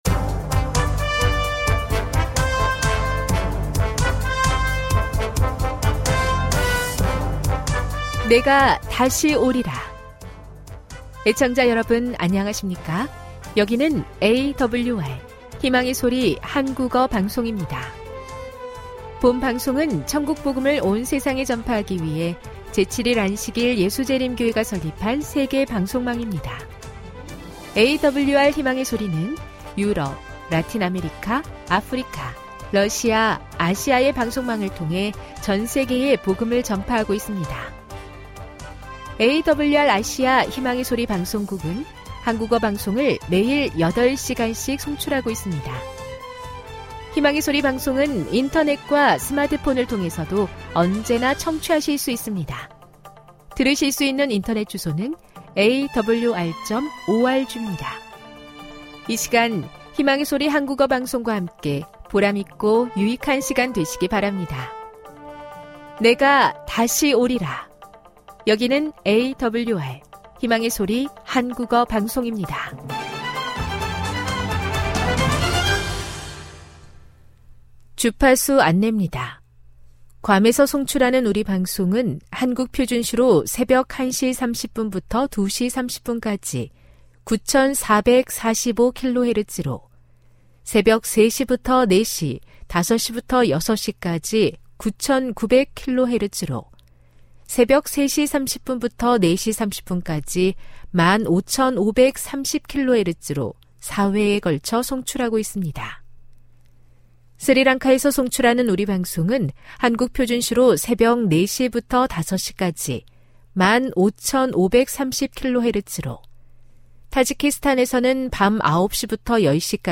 설교,건강한생활, 만나,명상의 오솔길